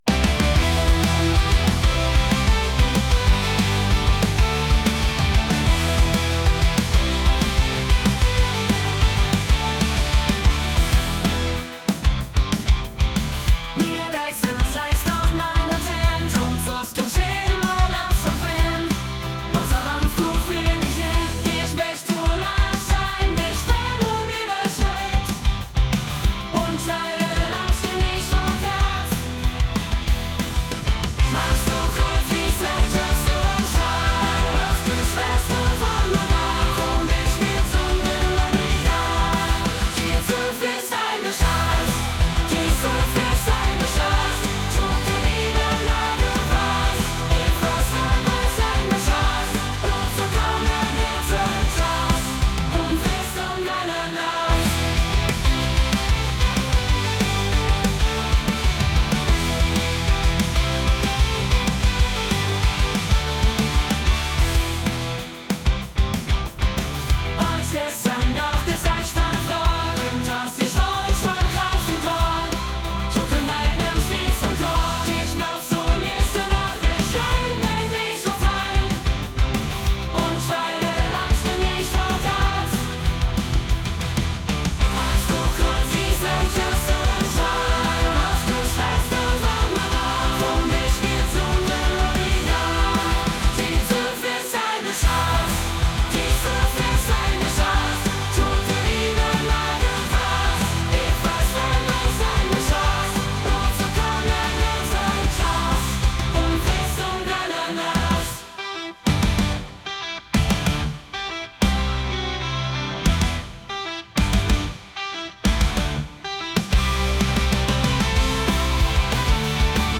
pop | rock | indie